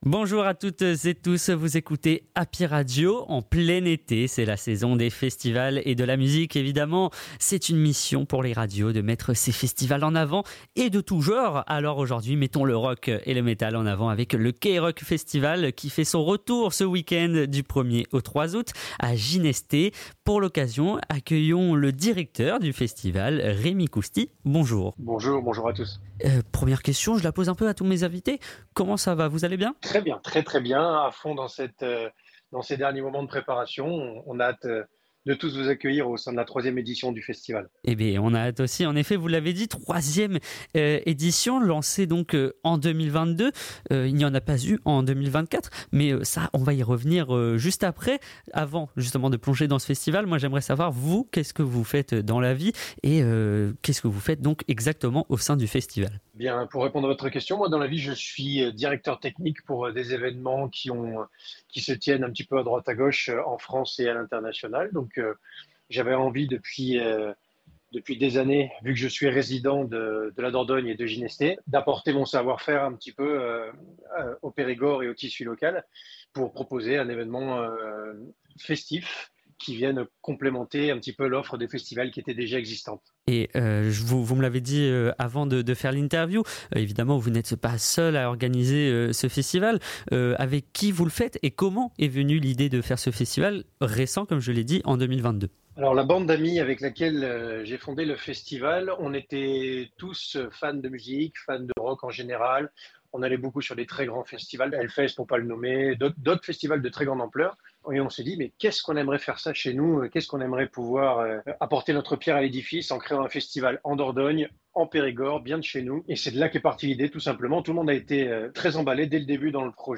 Les interviews Happy Radio